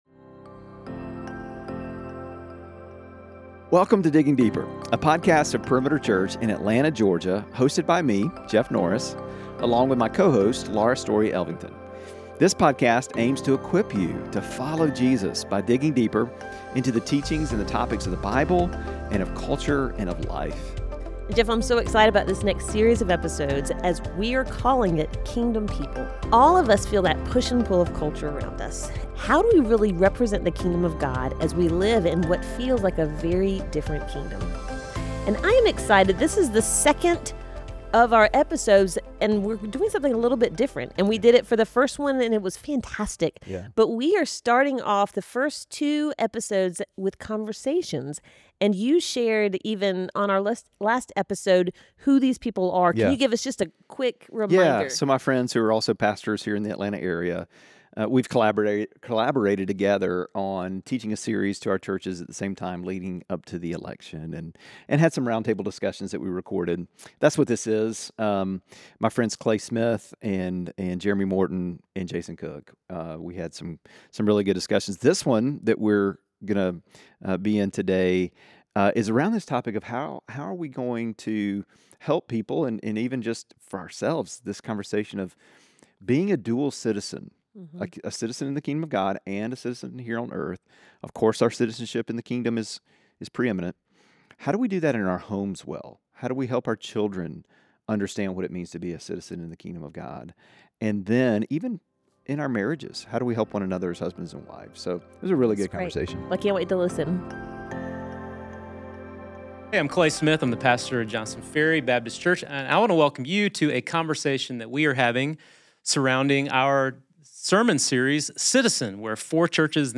Kingdom People: Pastor Roundtable Part 2 (; 11 Oct 2024) | Padverb
Today’s conversation focuses on how Christians navigate dual citizenship in both the kingdom of heaven and the worldly kingdom here on earth. They explore the importance of the Beatitudes in parenting, the challenges modern parents face, and the role of the church in supporting families. In this pastor roundtable, the men discuss practical advice for both parents and grandparents on how to model faith and encourage spiritual growth in children.